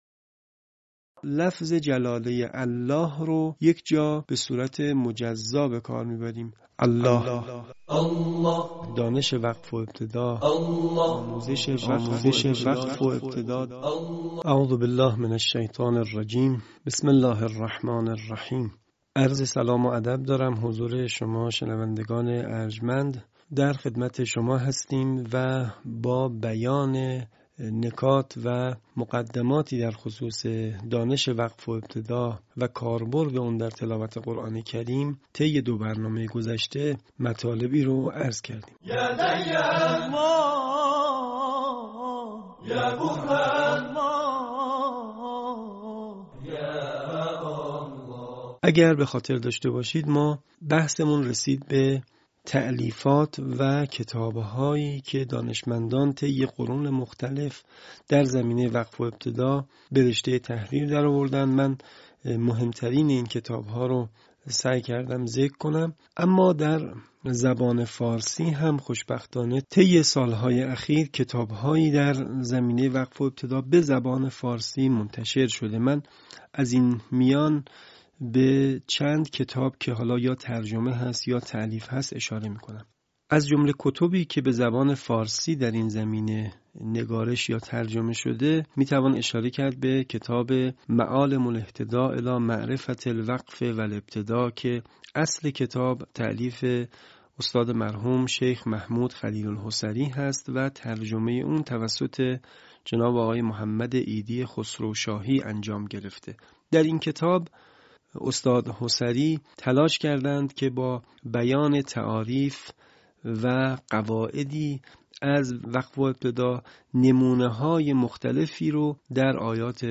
به همین منظور مجموعه آموزشی شنیداری(صوتی) قرآنی را گردآوری و برای علاقه‌مندان بازنشر می‌کند.